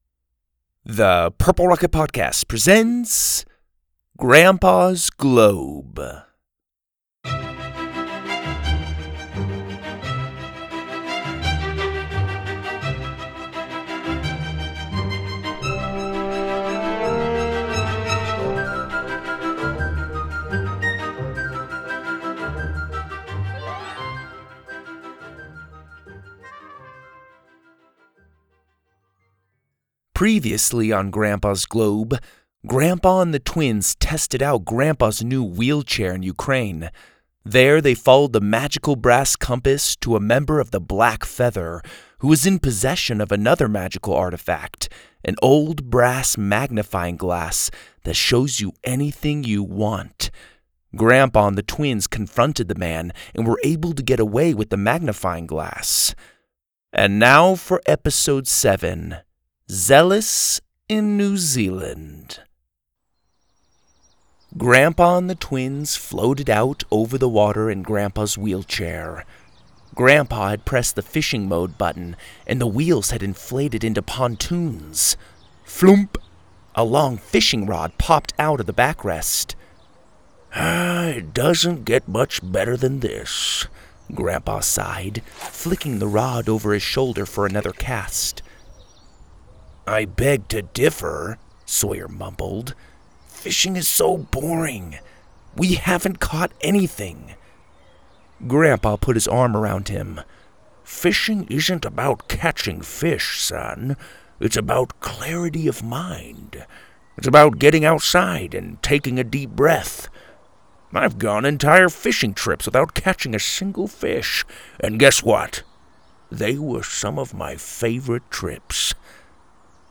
Stories For Kids, Kids & Family